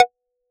edm-perc-12.wav